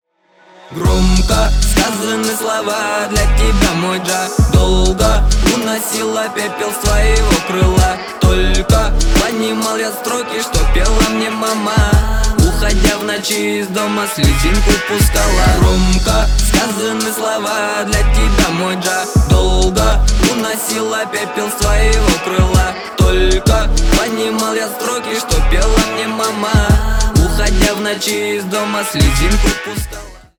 • Качество: 320 kbps, Stereo
Поп Музыка
грустные